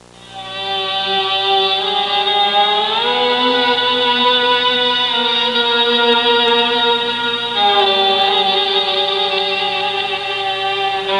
Arabian Intro Sound Effect
arabian-intro.mp3